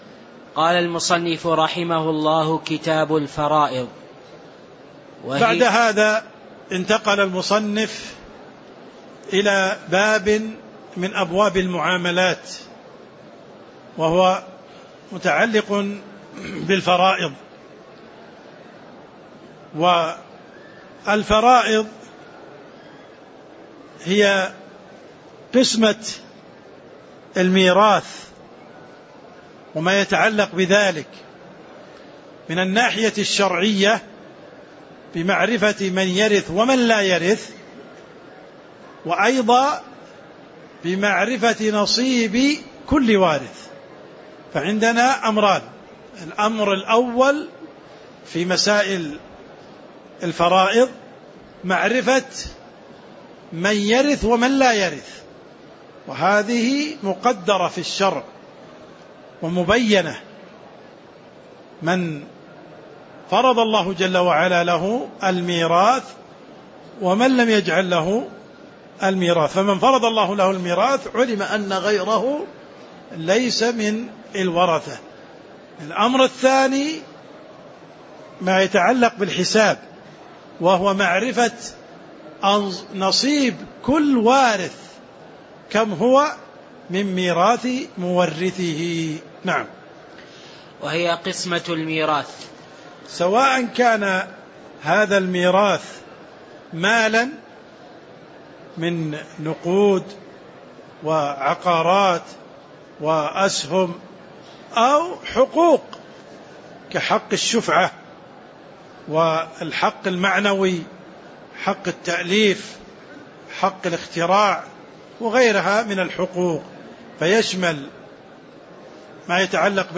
تاريخ النشر ٢٠ ربيع الأول ١٤٤٤ هـ المكان: المسجد النبوي الشيخ: عبدالرحمن السند عبدالرحمن السند قوله: وهي قسمة الميراث (01)